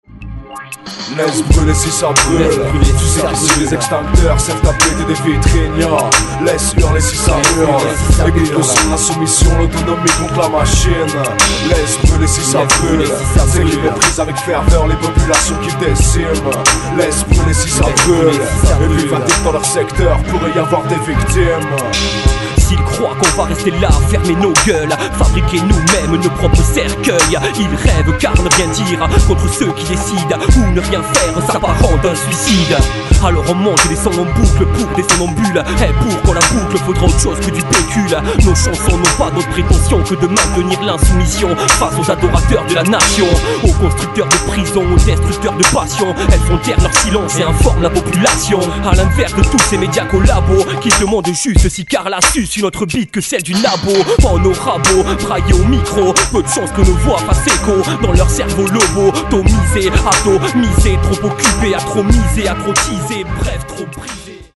Catégorie : Rap